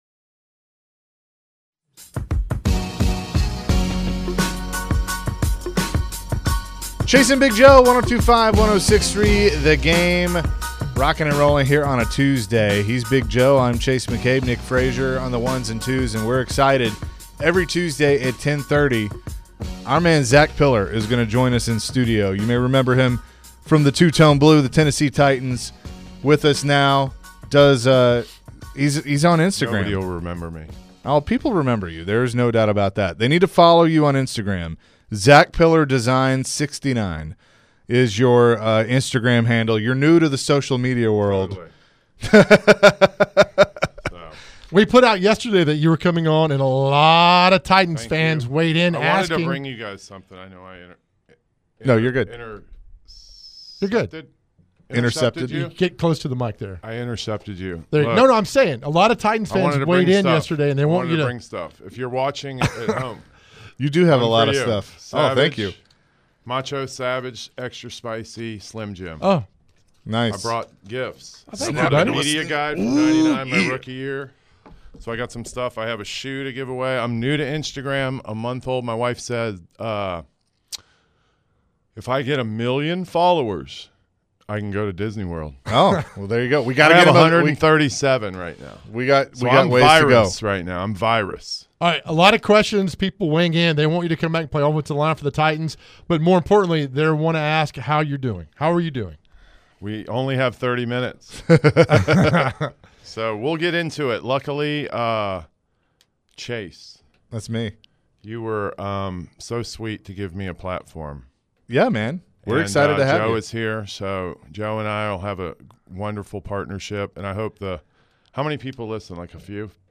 Former Titan Zach Piller joined the show and shared some stories from when he played football. Zach also talked about his size 17-foot shoe. Zach talked about the Titans offensive line issues.